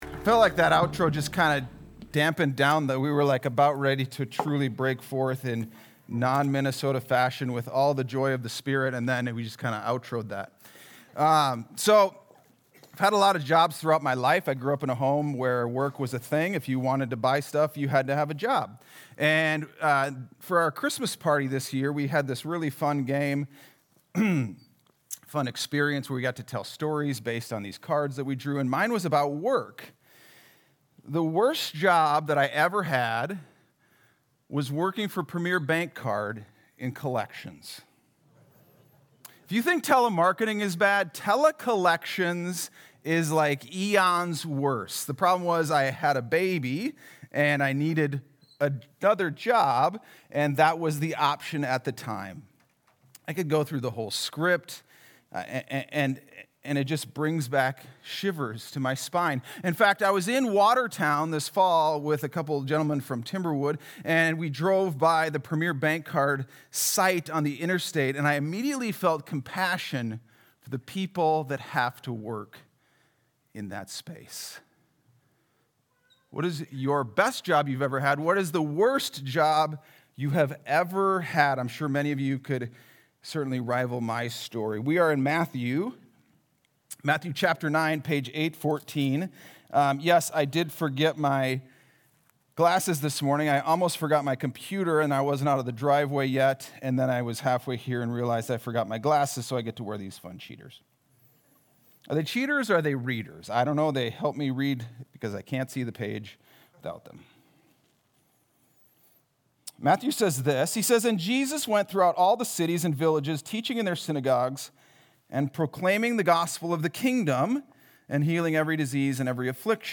Sunday Sermon: 2-8-26